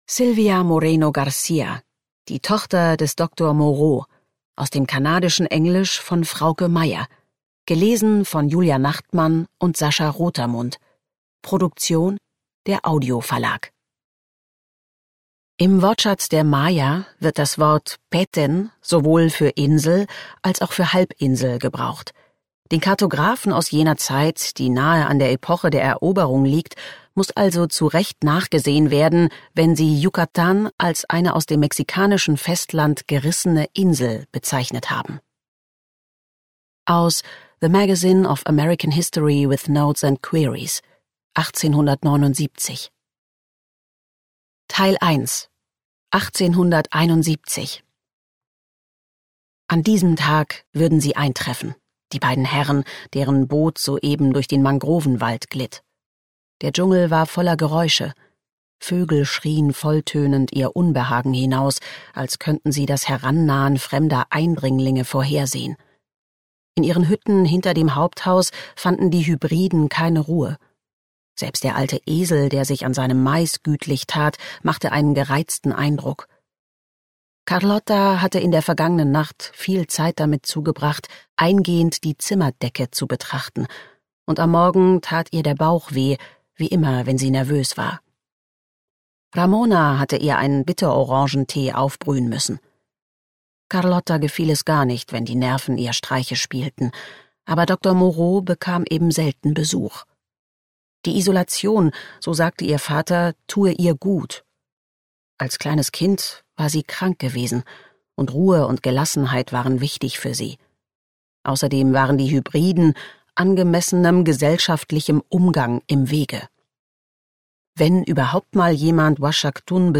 Themenwelt Literatur Romane / Erzählungen